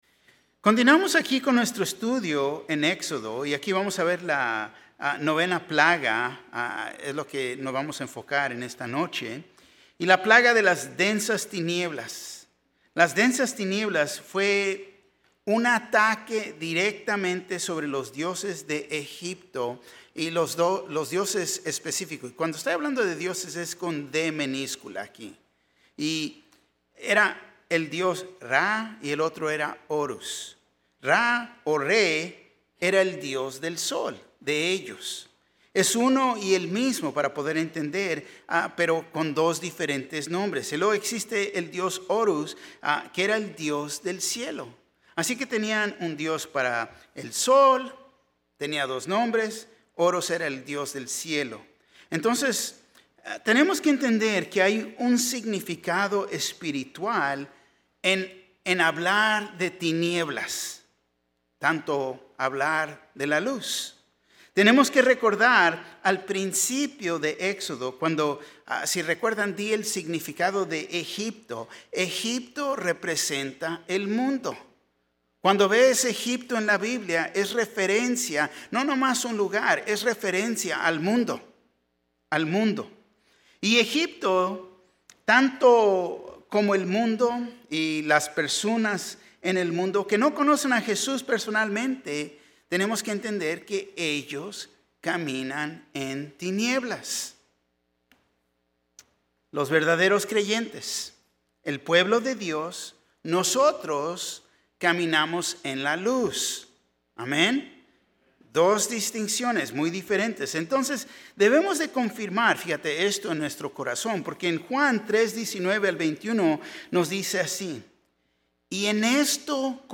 Un mensaje de la serie "Liberados."